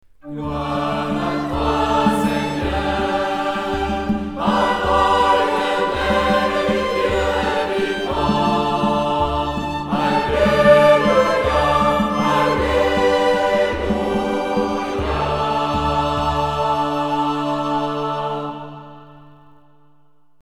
Deux offices des Laudes du temps ordinaire.